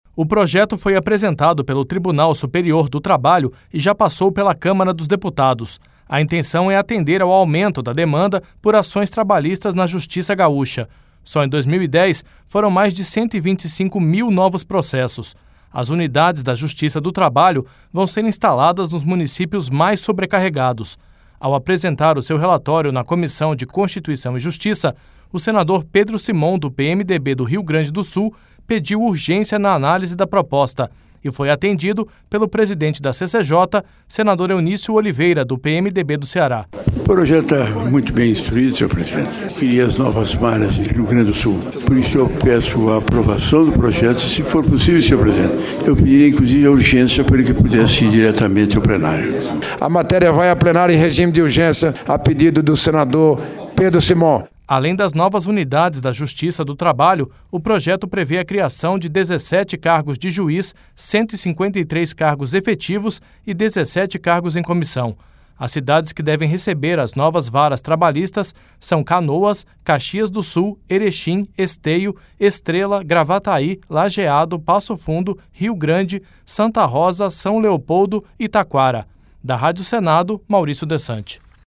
Ao apresentar o seu relatório na Comissão de Constituição e Justiça, o senador Pedro Simon, do PMDB do Rio Grande do Sul, pediu urgência na análise da proposta.